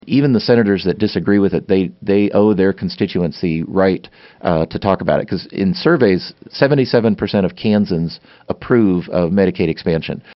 Lt. Gov. Lynn Rogers in-studio for KMAN's In Focus, Wednesday, April 3, 2019.